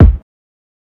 Kick (RealQuick).wav